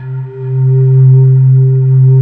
Index of /90_sSampleCDs/USB Soundscan vol.28 - Choir Acoustic & Synth [AKAI] 1CD/Partition D/19-IDVOX FLT